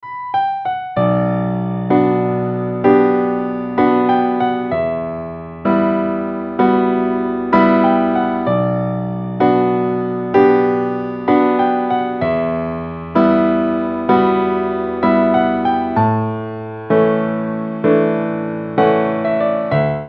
Relaksacja i odpoczynek !
Idealne jako kołysanki bądź muzyka relaksacyjna.